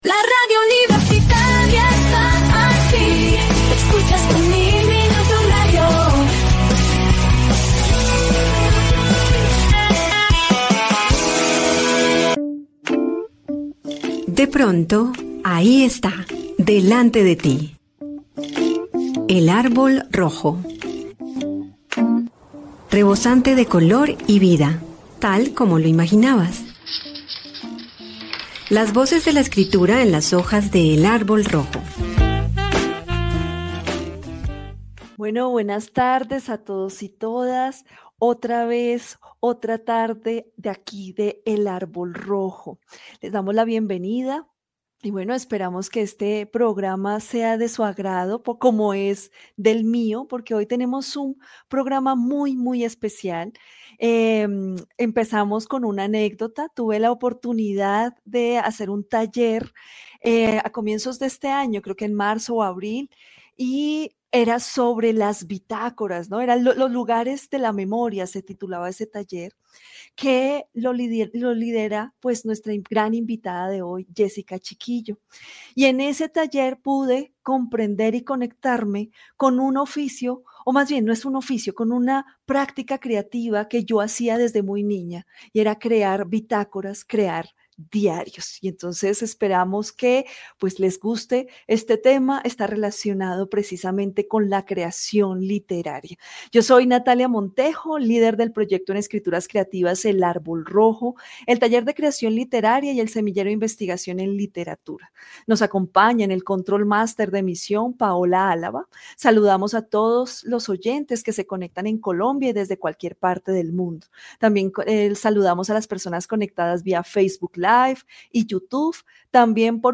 Comentario al Evangelio de hoy